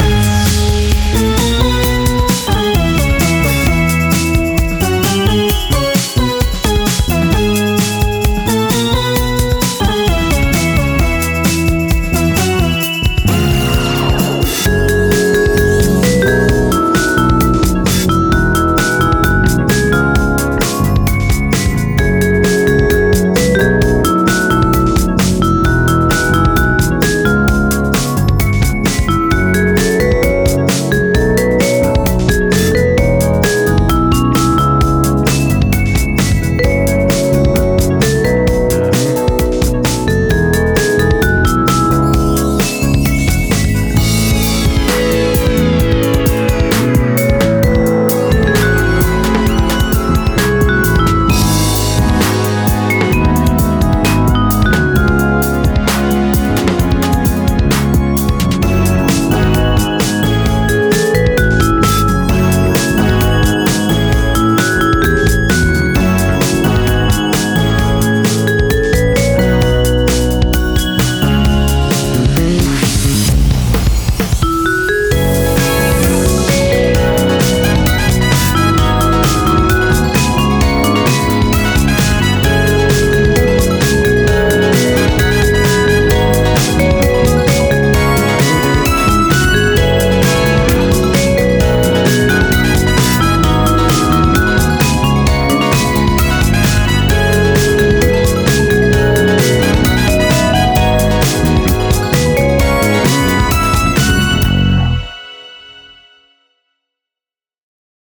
サンプル②（メジャーキー）